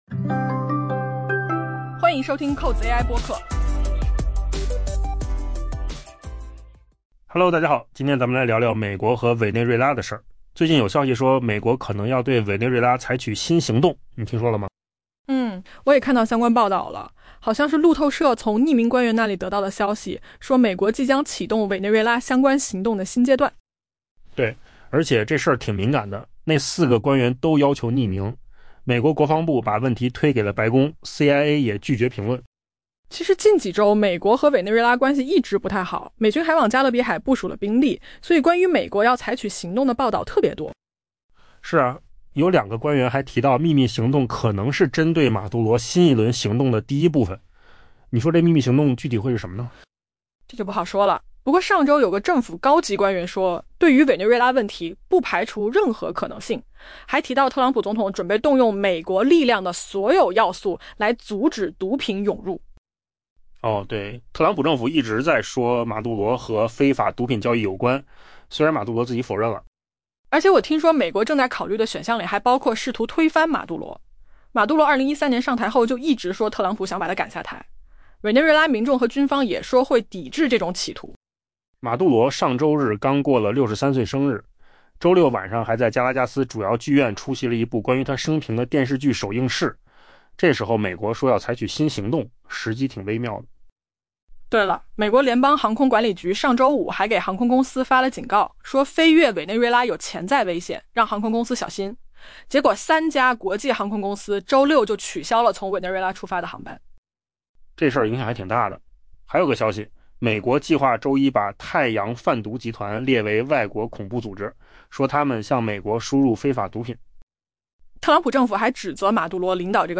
AI 播客：换个方式听新闻 下载 mp3 音频由扣子空间生成 四名美国官员向路透社透露，随着特朗普政府加大对尼古拉斯·马杜罗 （Nicolas Maduro） 政府的施压， 美国即将在未来数日启动委内瑞拉相关行动的新阶段。